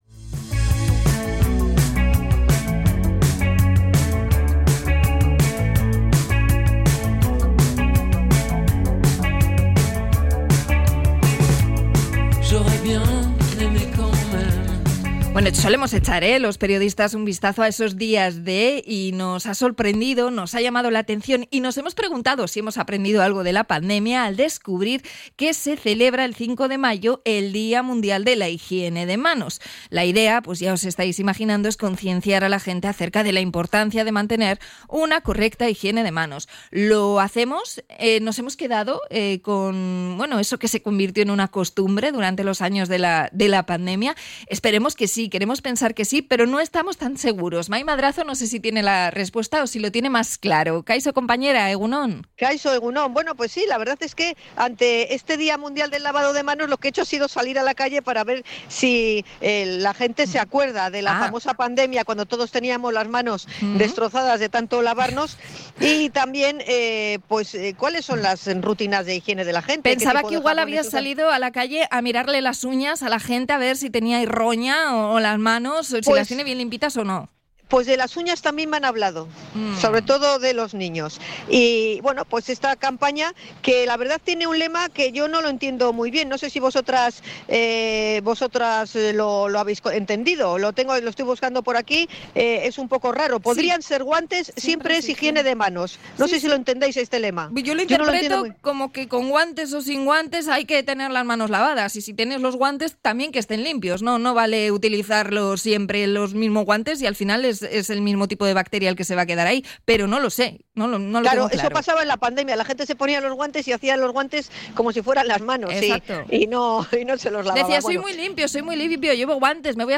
Hablamos con vecinos de Bilbao acerca de sus costumbres higiénicas
Hemos salido a las calles de Bilbao para realizar una encuesta entre los transeúntes y conocer su opinión sobre el lavado de manos.